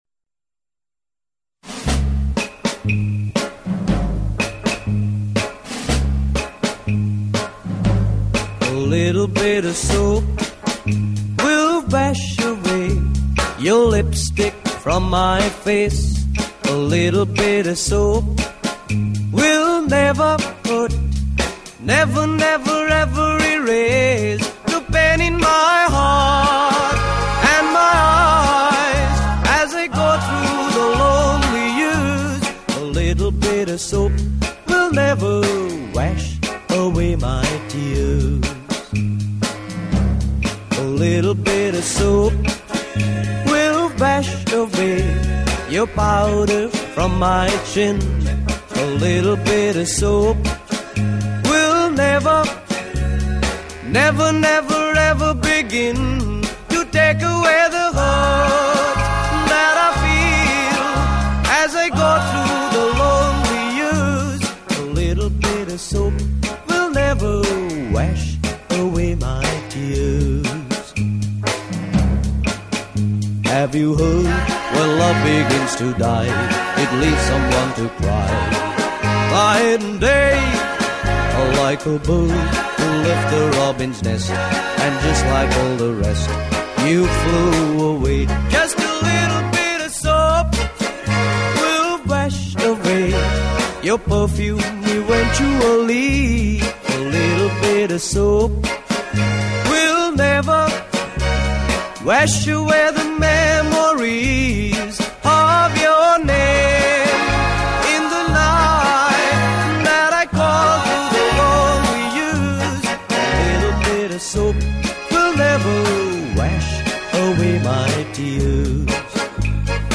节奏明快